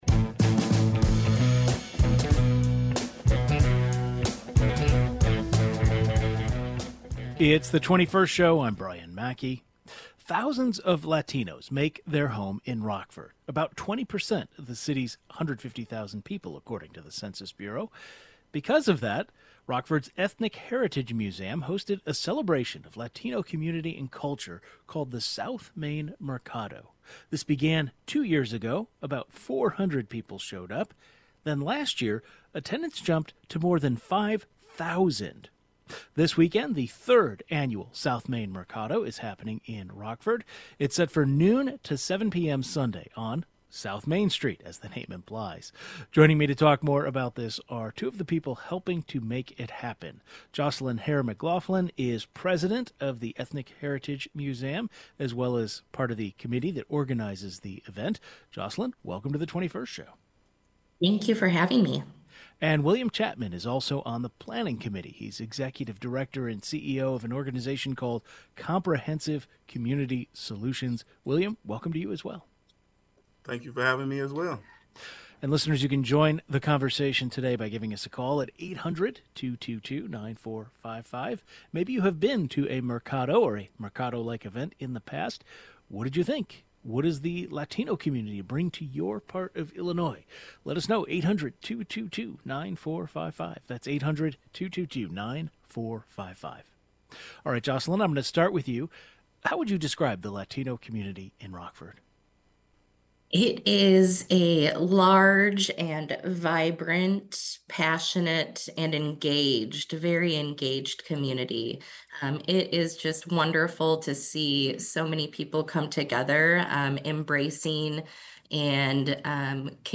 We talk with the organizers of Rockford's South Main Mercado, which takes place this Sunday from noon to 7 p.m.